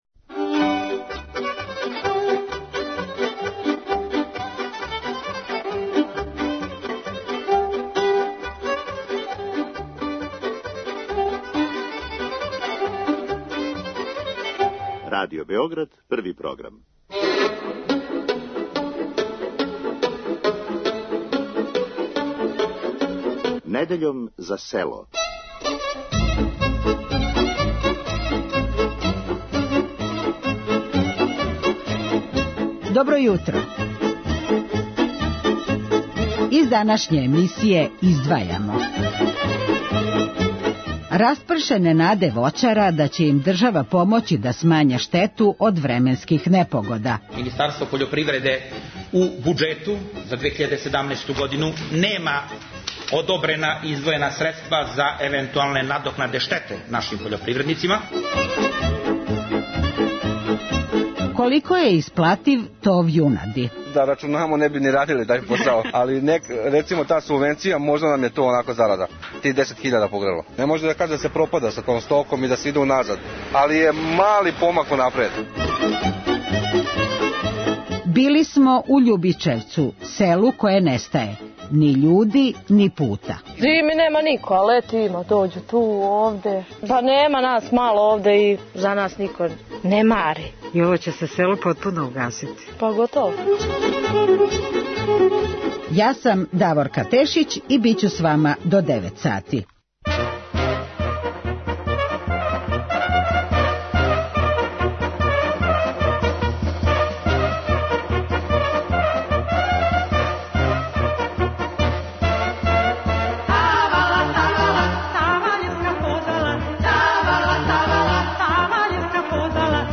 О томе колико је уносан тов јунади, али и друге врсте пољопривредне производње, разговарали смо са младим сточаром